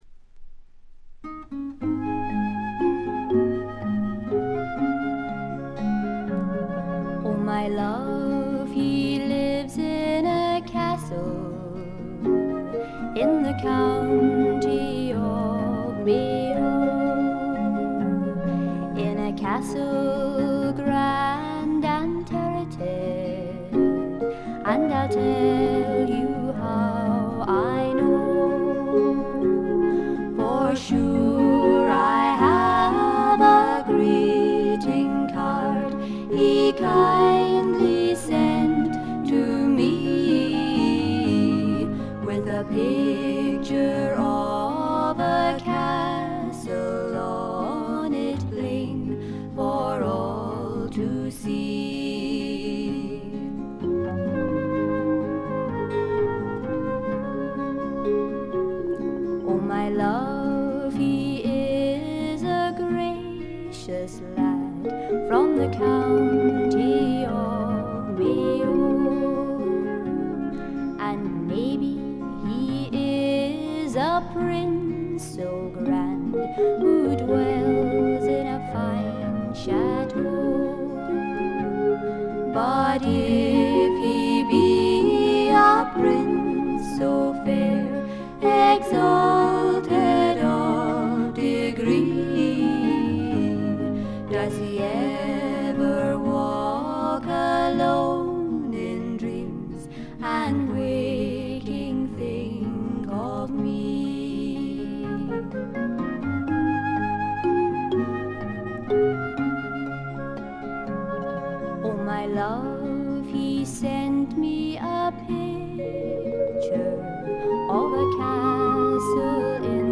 軽微なチリプチやバックグラウンドノイズ、散発的なプツ音少々。
内容はまさしく天使の歌声を純粋に楽しめる全14曲です。
アレンジはあくまでもフォークであってギターのアルペジオ主体の控え目なものです。
試聴曲は現品からの取り込み音源です。